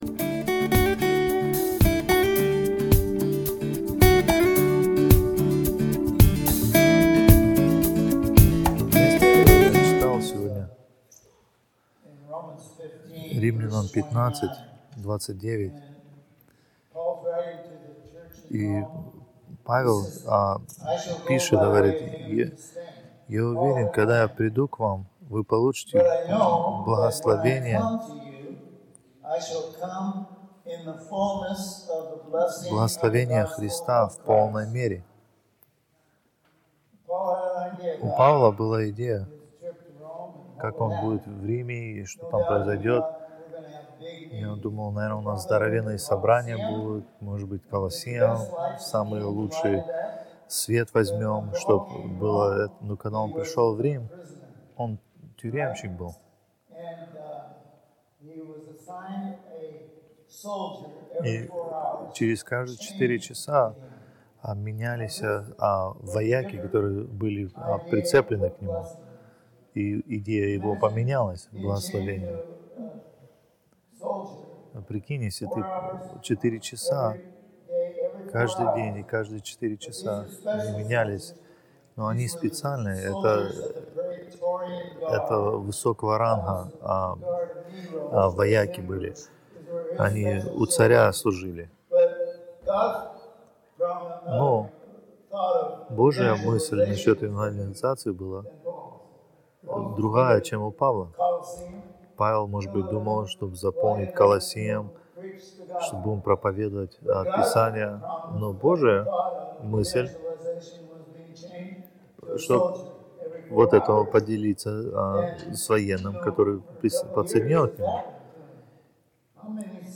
Русские проповеди Приведение Каждой Помысли Во Христа.